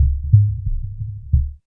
50 RBT HEART.wav